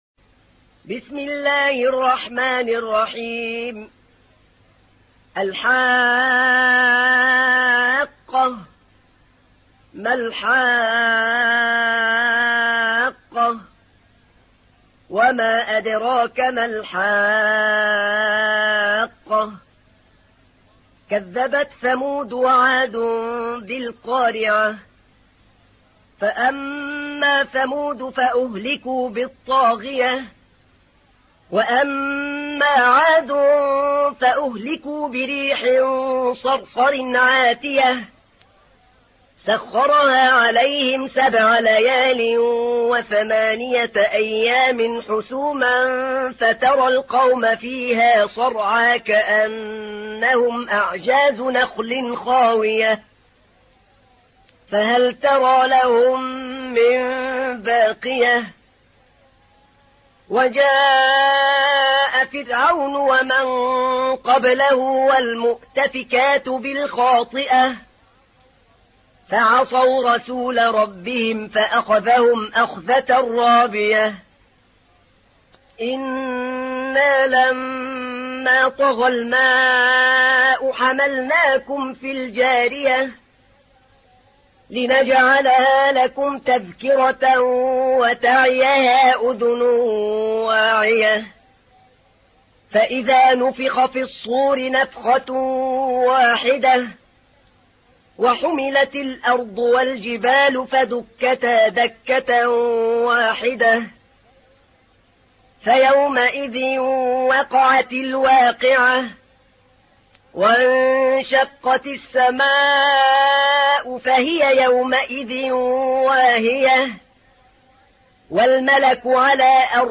تحميل : 69. سورة الحاقة / القارئ أحمد نعينع / القرآن الكريم / موقع يا حسين